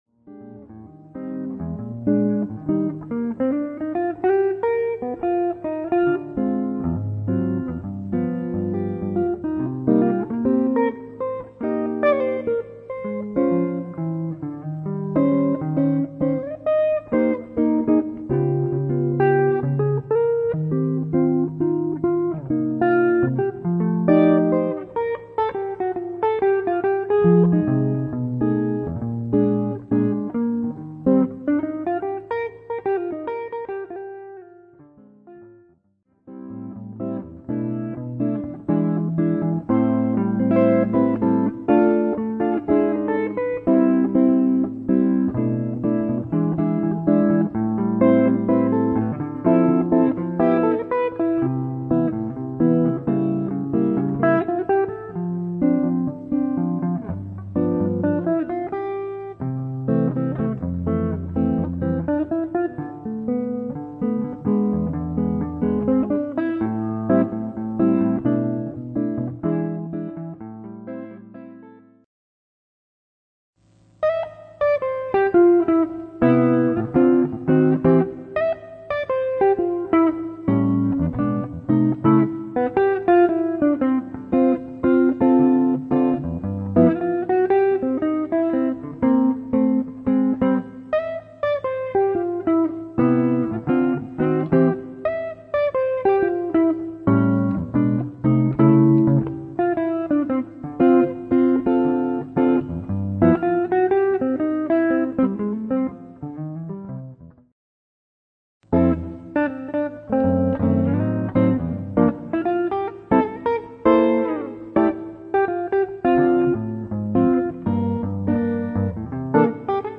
solo jazz guitar
Solo Guitar